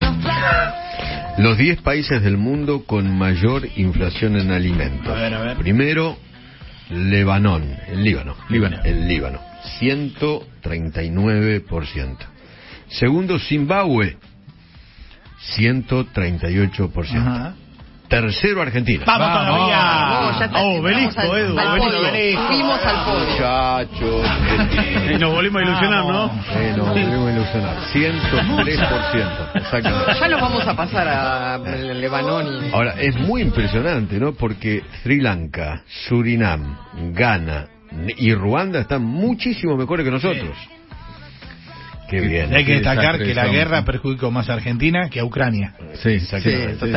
El conductor de Alguien tiene que decirlo se refirió al ranking mundial de los países con más inflación en alimentos, dentro del cuál Argentina se encuentra tercero.